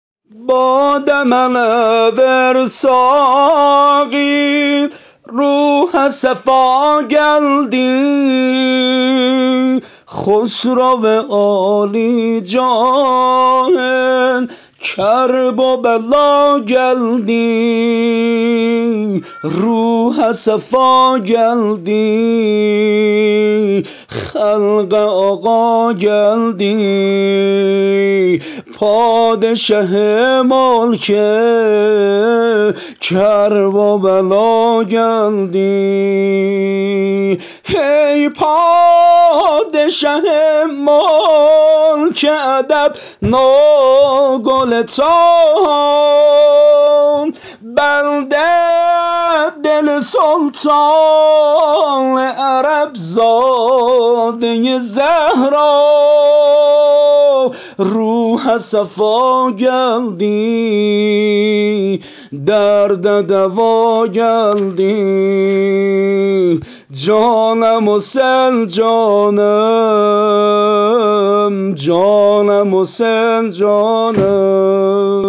متن شعر ترکی میلادیه حضرت اباعبدالله الحسین علیه السلام -(باده منه وئر ساقی ٬ روحه صفا گلدی)
سبک: منتظرت بودم...